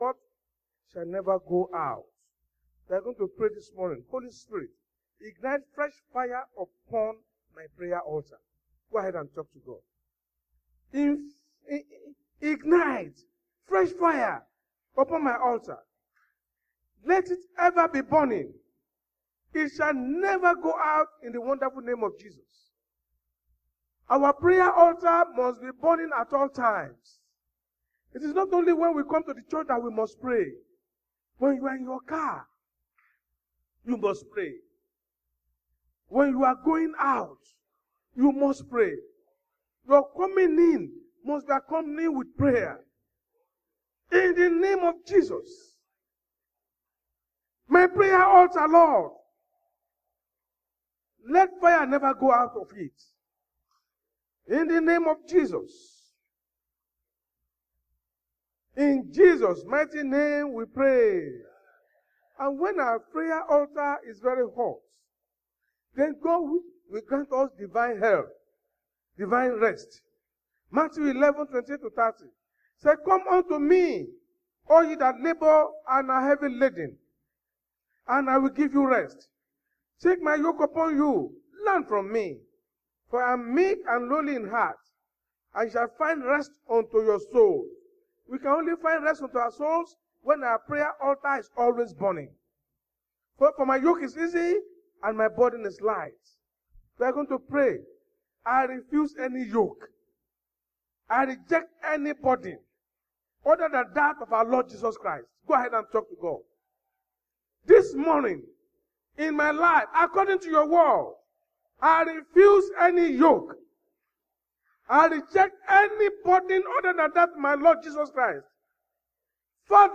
RCCG-House Of Glory's sunday message.
Service Type: Sunday Church Service